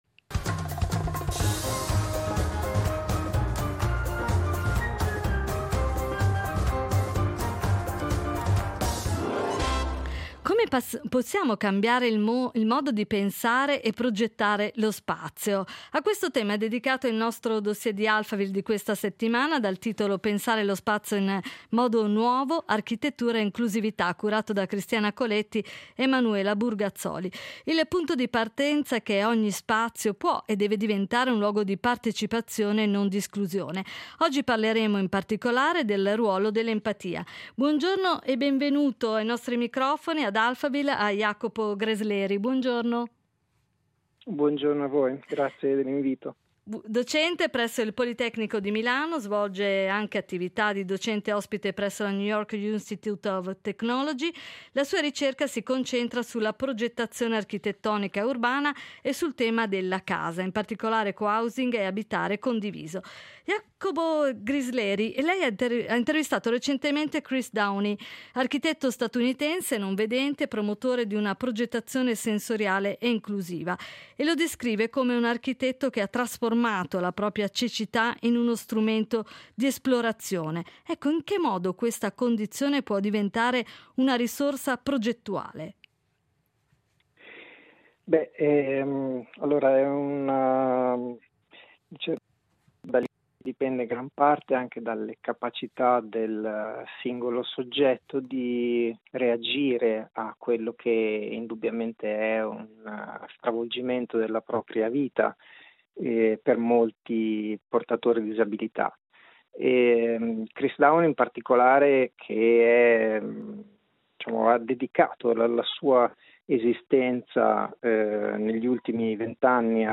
Discussione